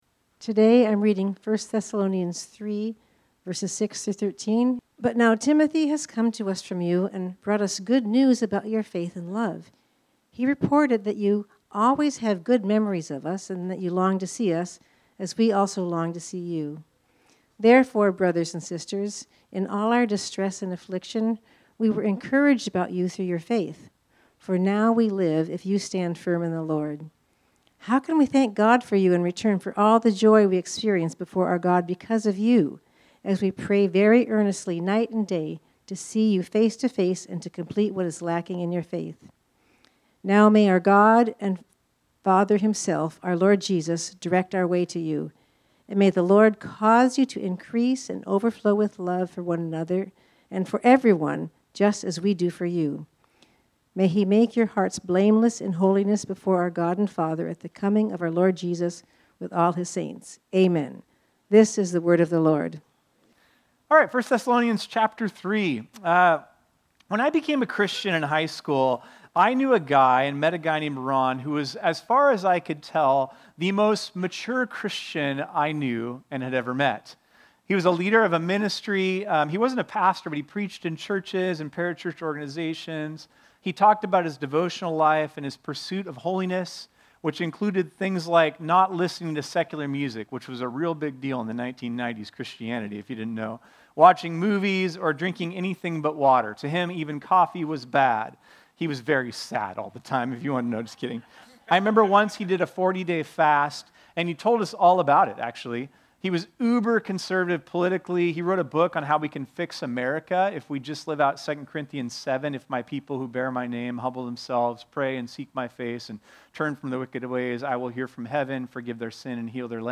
This sermon was originally preached on Sunday, July 13, 2025.